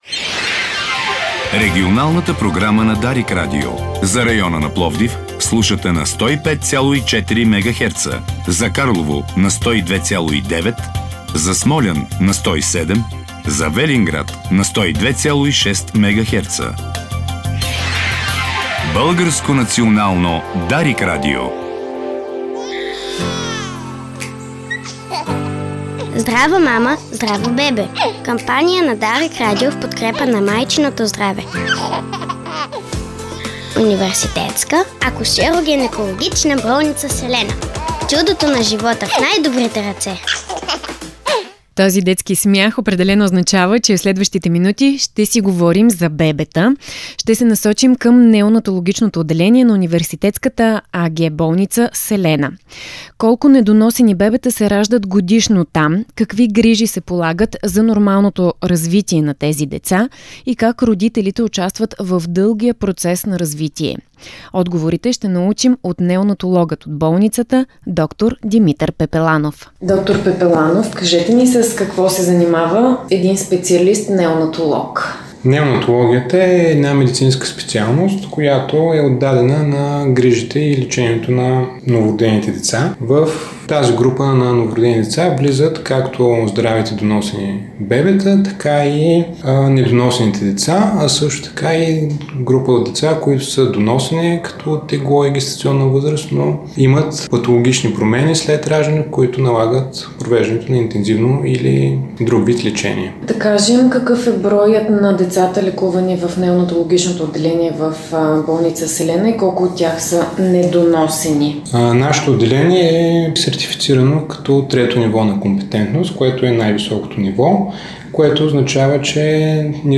Отговорите ще научим от неонатологът